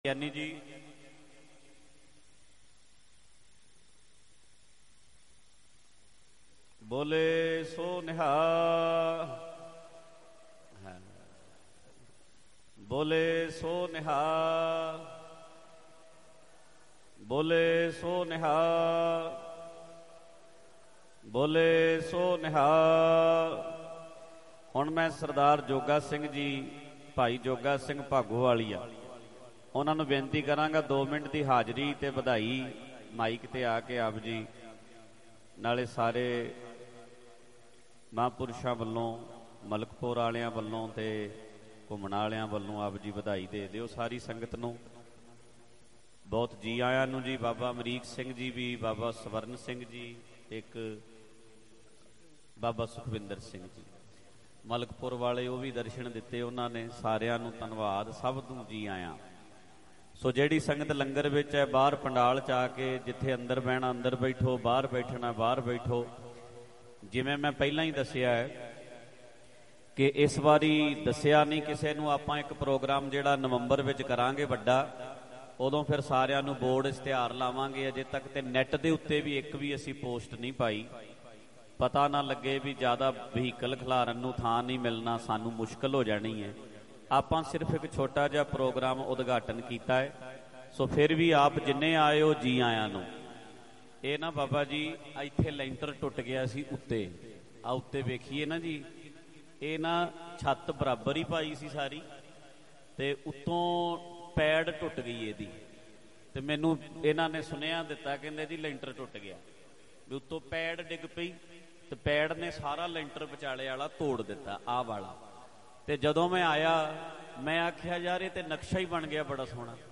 Dhadrianwale Live From Parmeshar Dwar 19 Nov 2023 | DhadrianWale Diwan Audios mp3 downloads gurbani songs
Mp3 Diwan Audio by Bhai Ranjit Singh Ji Dhadrian wale at Parmeshardwar